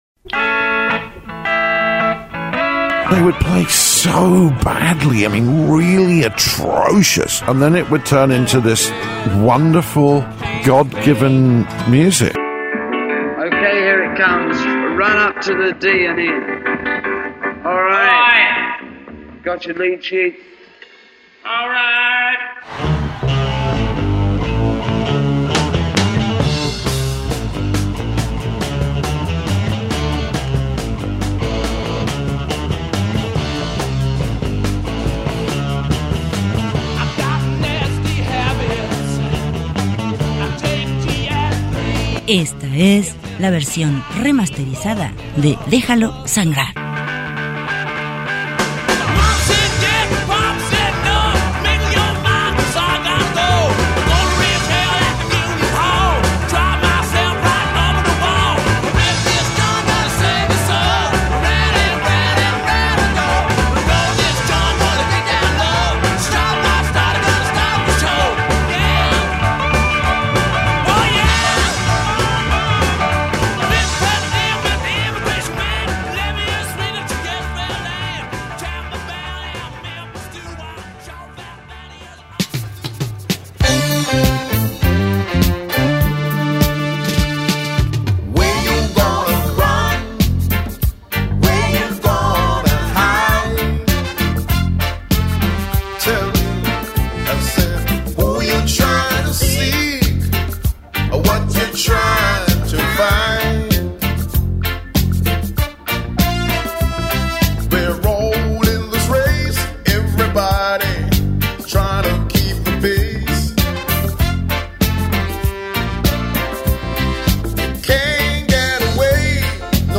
Playlist miércoles 4 marzo ‘26